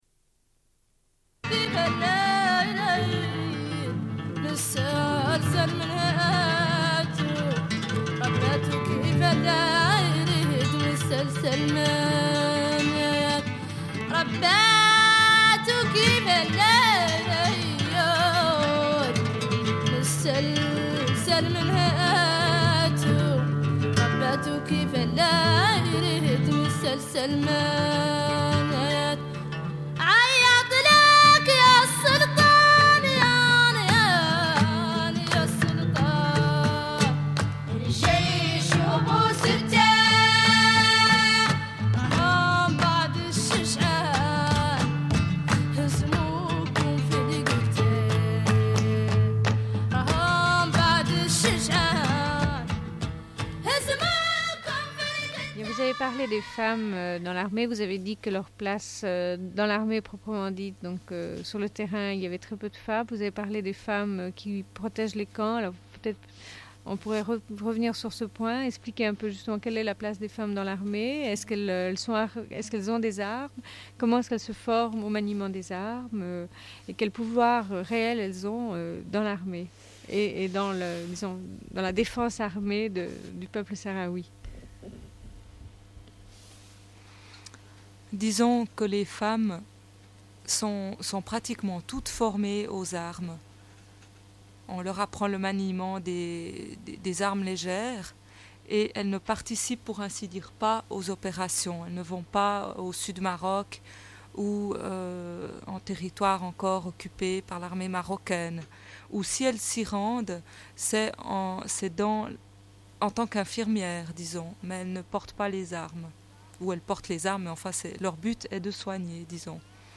Les femmes du Comité Sahraoui parlent de la lutte des femmes Sahraouis.
Radio Enregistrement sonore